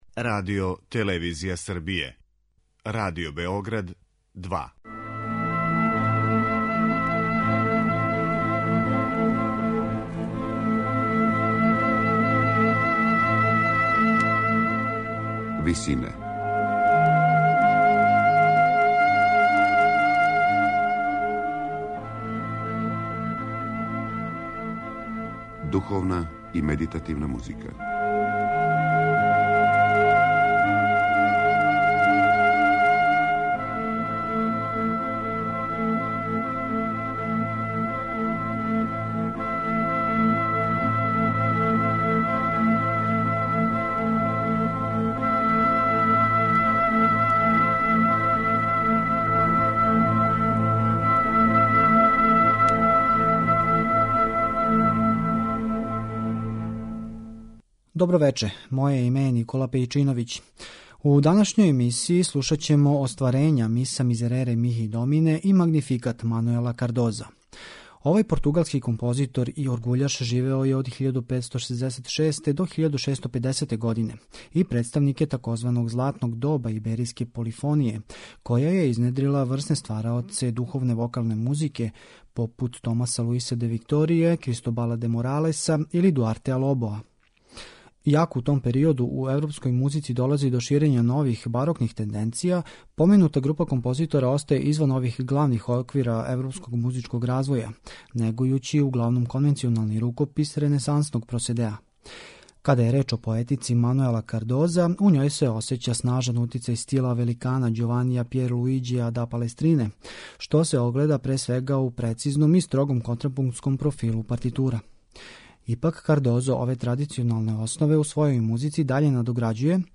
Духовна и медитативна музика